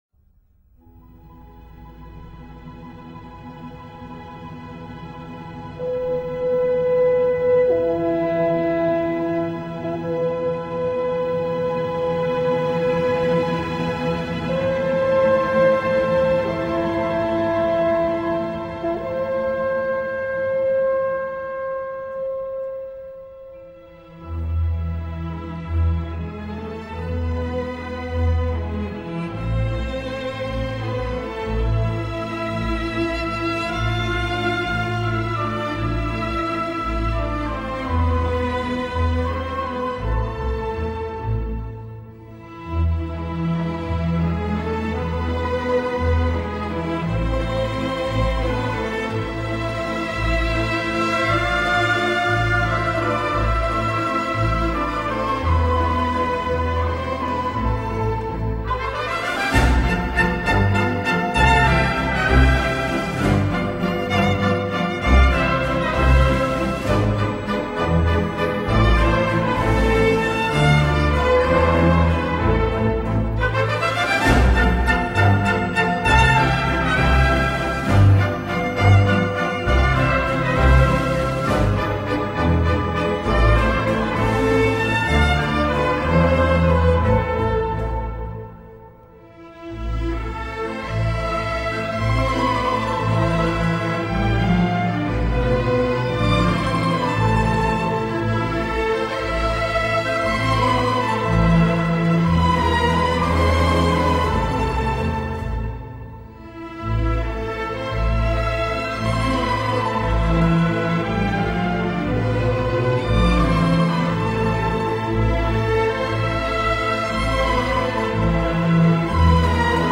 Чудесный вальс, не менее замечательное исполнение!